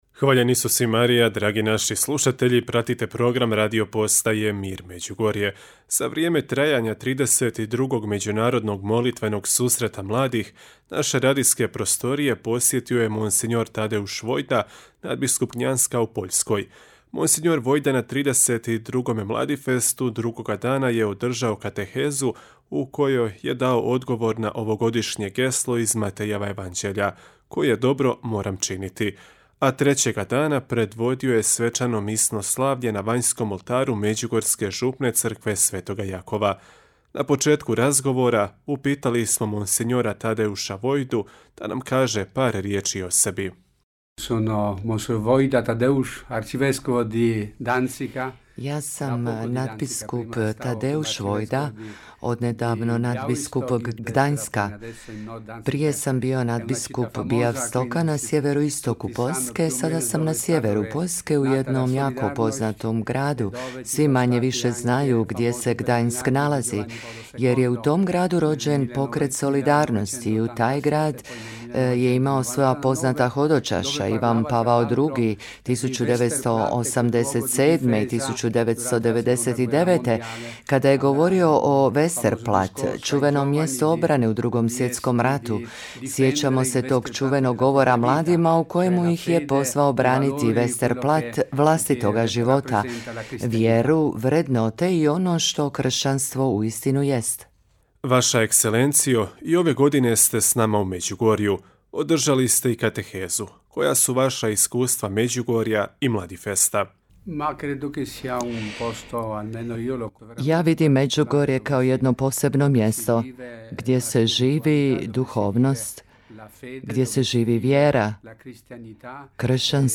Dao je intervju u kojemu je, između ostaloga, govorio i o Međugorju.